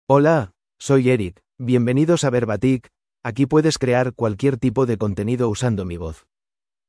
Eric — Male Spanish (Spain) AI Voice | TTS, Voice Cloning & Video | Verbatik AI
MaleSpanish (Spain)
Eric is a male AI voice for Spanish (Spain).
Voice sample
Eric delivers clear pronunciation with authentic Spain Spanish intonation, making your content sound professionally produced.